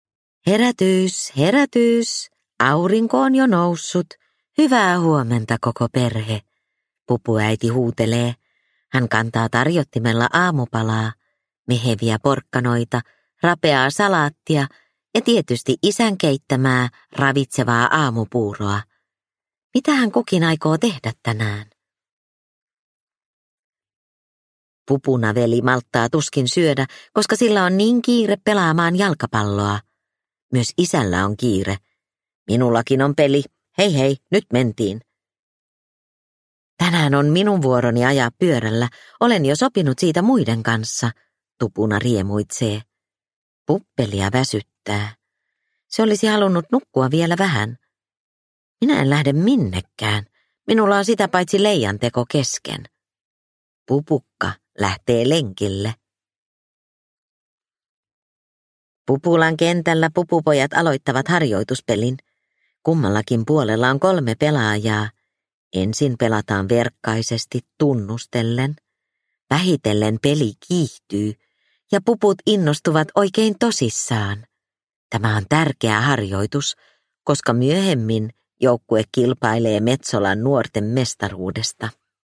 Pupu Tupuna - Pupulassa harrastetaan – Ljudbok – Laddas ner